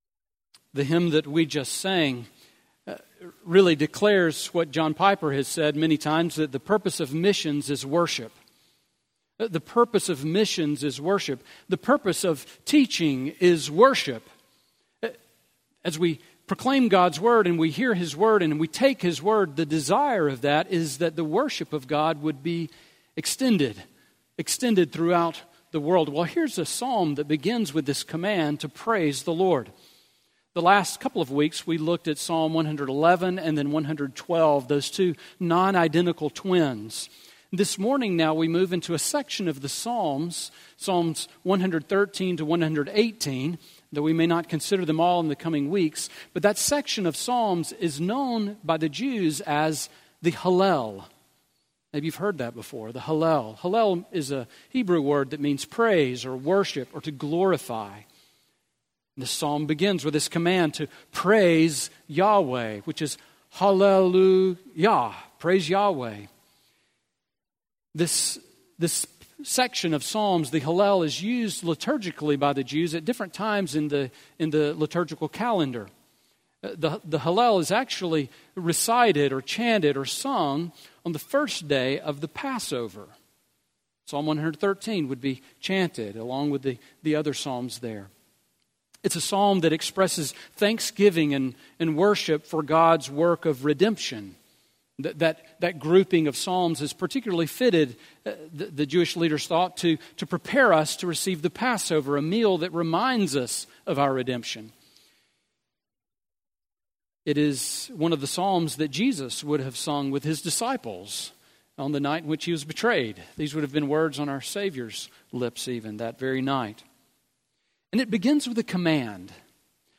Sermon Audio from Sunday
Sermon on Psalm 113 from August 12